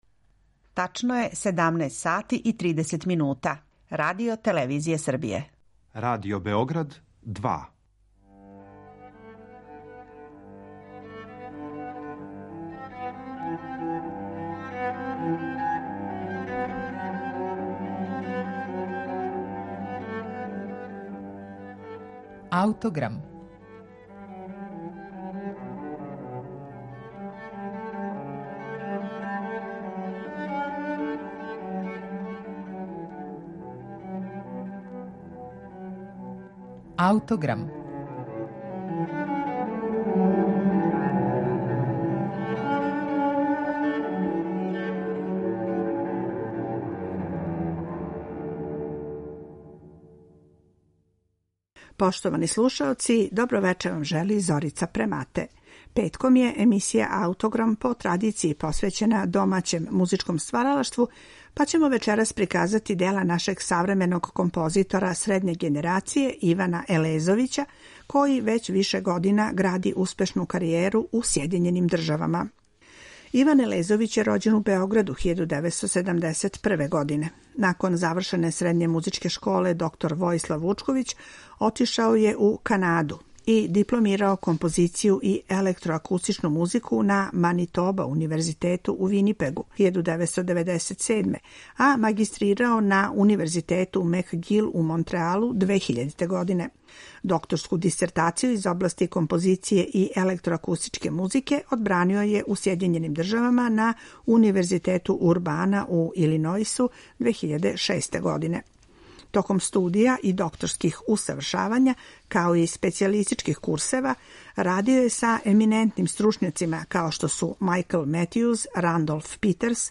за виолончело соло